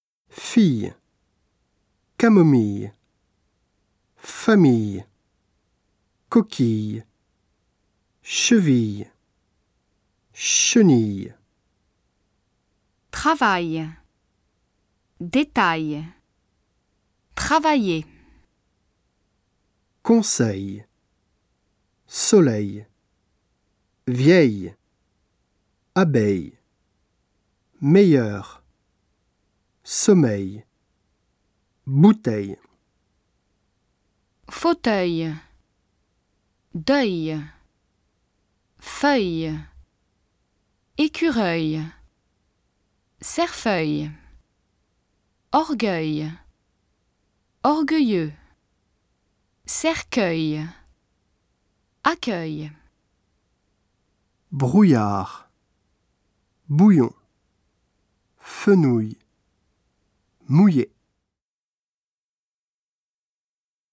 トップページ > 綴り字と発音 > ３ 綴り字と発音 3 綴り字と発音 3)il,illグループ 綴り字 発音記号 例 発音上の注意 ill [ij] f ill e camom ill e fam ill e coqu ill e chev ill e chen ill e 例外：ville[vil] mille[mil] tranquille[trɑ̃nkil] ail/aill [aj] trav ail dét ail trav aill er eil/eill [ɛj] cons eil sol eil vi eill e ab eill e m eill eur somm eil bout eill e euil/euill [œj] faut euil d euil f euill e écur euil cerf euil ueil/ueill org ueil org ueill eux cerc ueil acc ueil ouil/ouill [uj] br ouill ard b ouill on fen ouil m ouill é １ アルファベ（Alphabet） ２ 母音 ３ 綴り字と発音 ４ リエゾン liaison ５ アンシェヌマンenchaînement ６ エリズィオン élision ７ 句読記号
06 Prononciation - 3_3.mp3